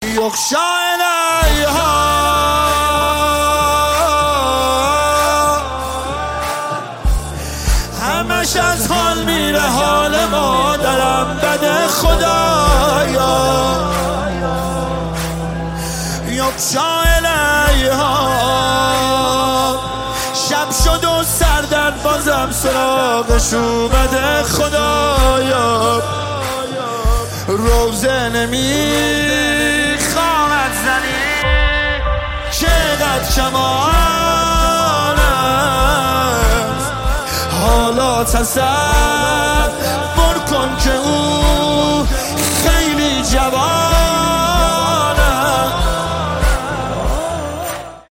نماهنگ دلنشین
مداحی مذهبی